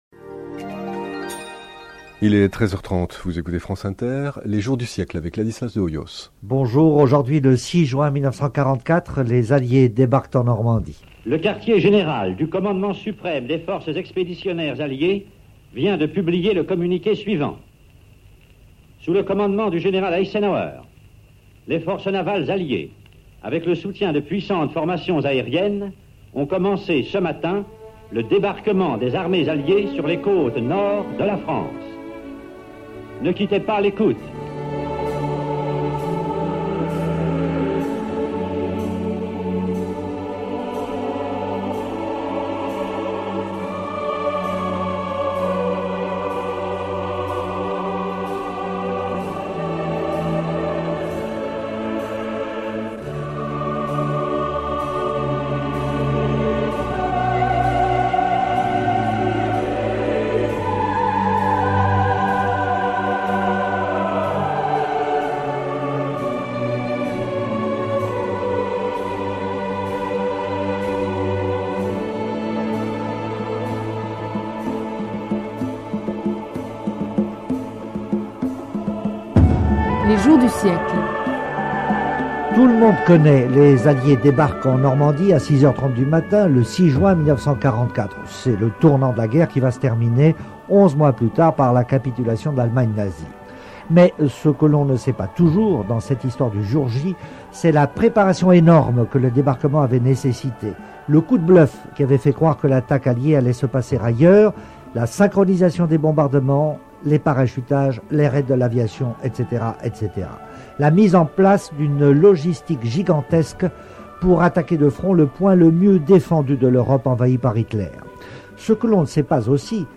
Interview réalisée le 6 Juin 1998, avec Ladislas de Hoyos sur France Inter, dans l’émission Les Jours du Siècle.